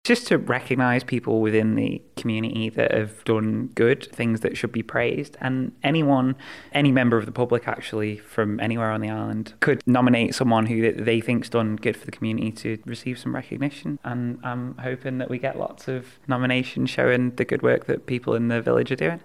Chair of the local authority Zara Lewin hopes there’ll be lots of recommendations: